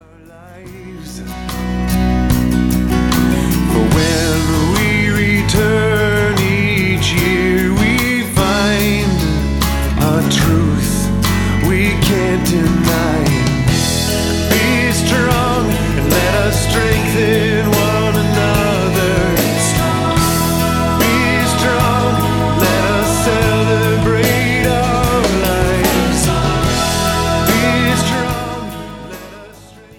hooky intellectual rock
gorgeous, introspective liturgical modern standards